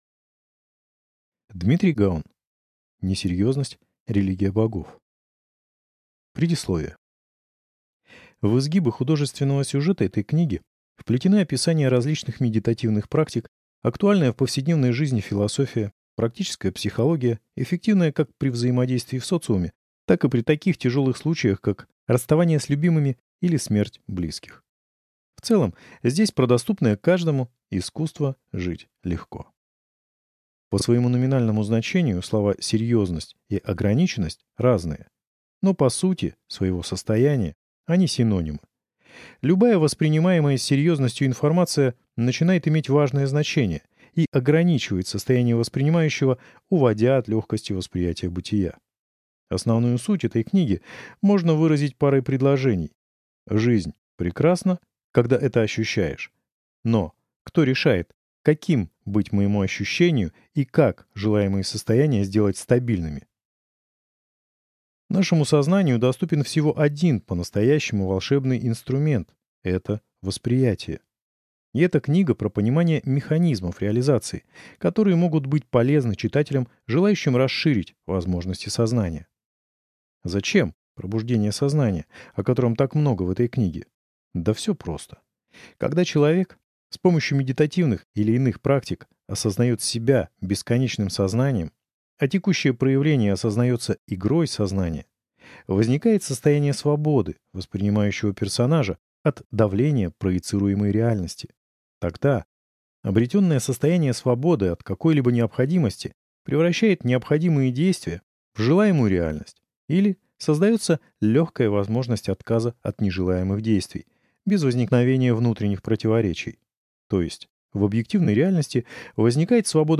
Аудиокнига Несерьёзность – религия богов | Библиотека аудиокниг